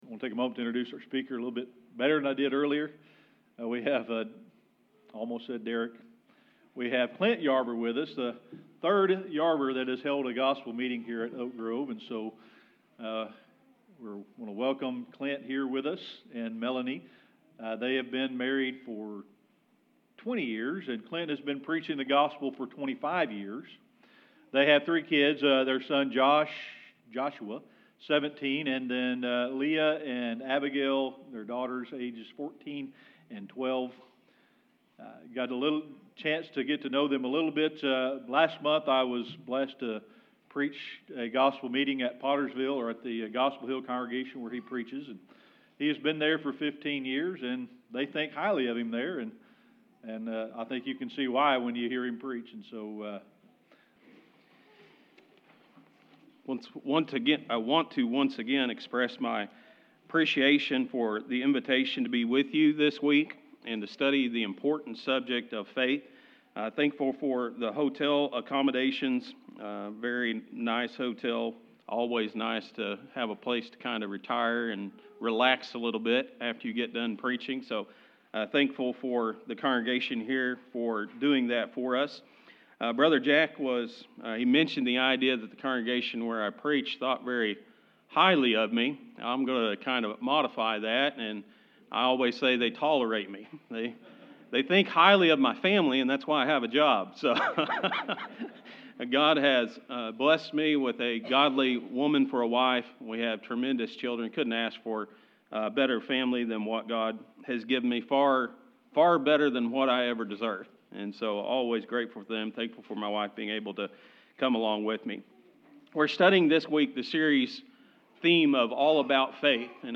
A lesson about Noah and how hea had the faith to work for God. Noah SCULPTED the ark by faith, through faith he was SAVED.